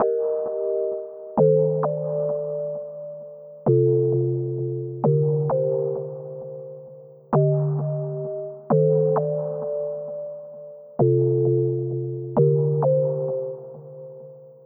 Drake Type Loop.wav